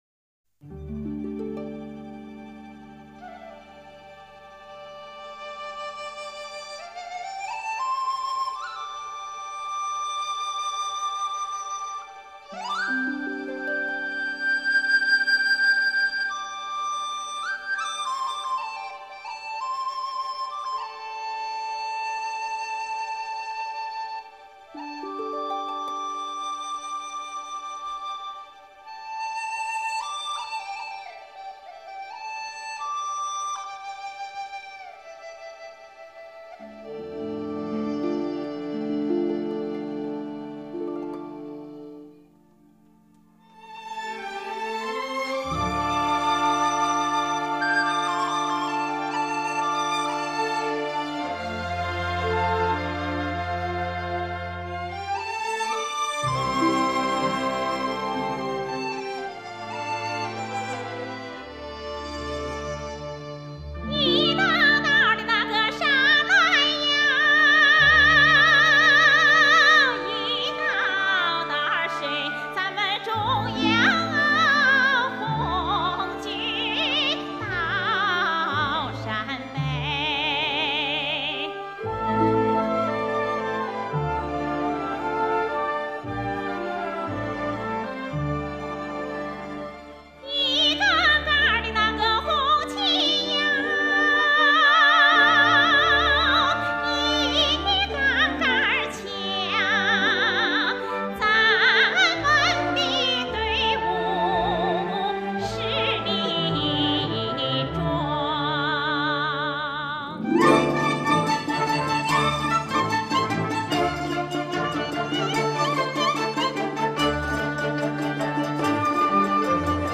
被誉为"黄土高原上的银铃"